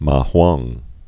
(mä-hwäng)